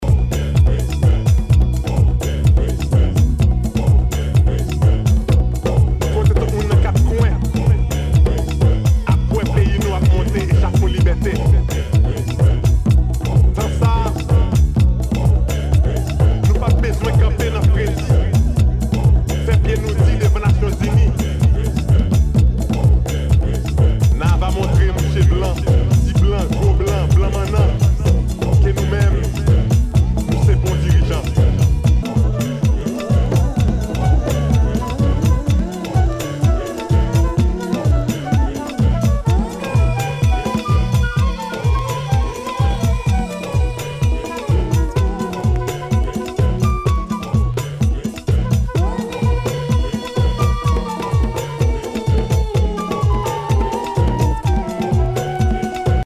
HOUSE/TECHNO/ELECTRO
ナイス！ディープ・ハウス！